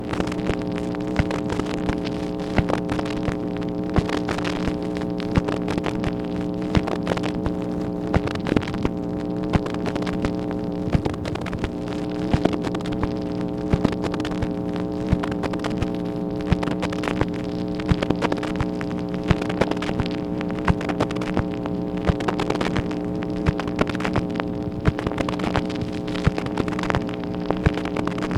MACHINE NOISE, September 24, 1964